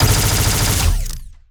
Plasmid Machinegun
GUNAuto_Plasmid Machinegun Burst_06_SFRMS_SCIWPNS.wav